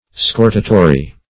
Search Result for " scortatory" : The Collaborative International Dictionary of English v.0.48: Scortatory \Scor"ta*to*ry\, a. [L. scortator a fornicator, from scortari to fornicate, scortum a prostitute.]